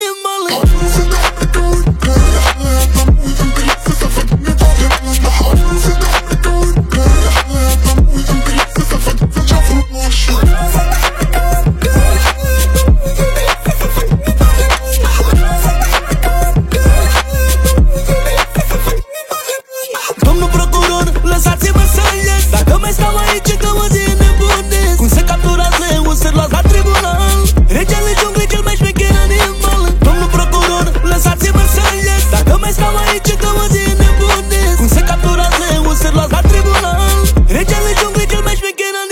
Kategorien Rap/Hip Hop